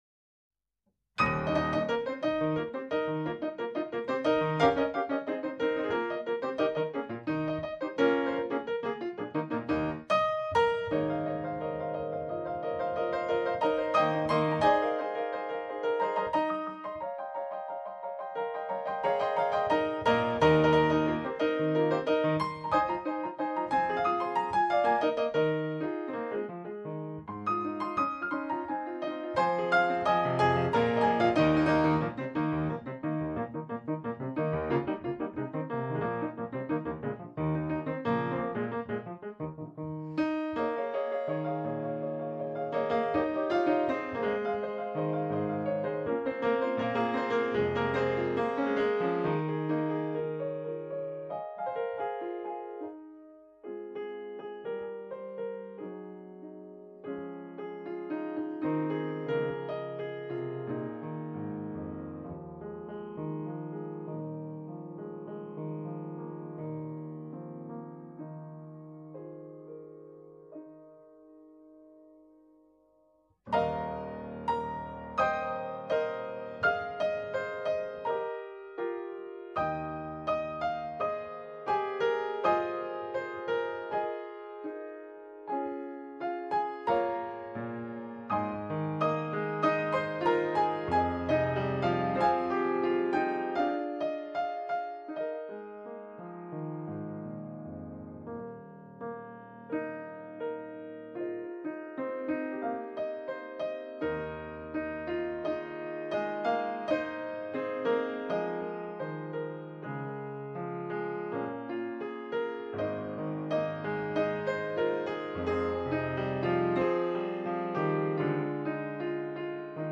0451-钢琴名曲翻身道情.mp3